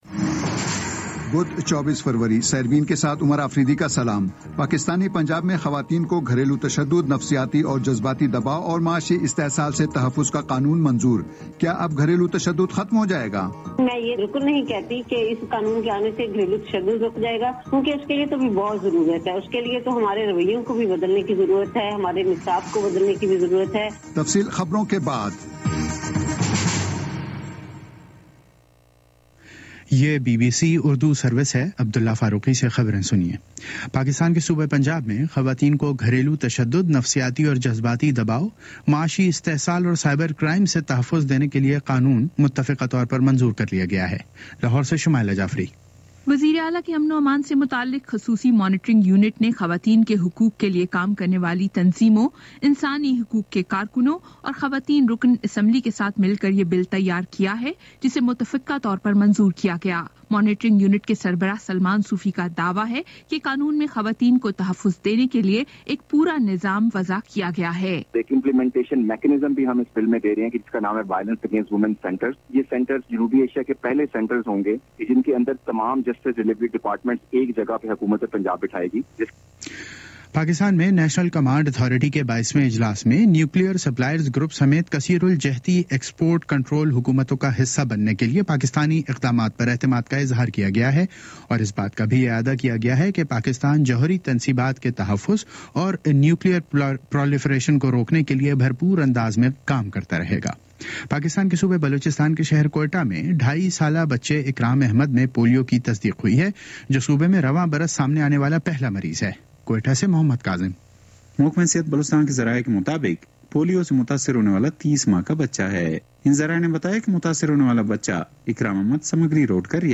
بدھ 24 فروری کا سیربین ریڈیو پروگرام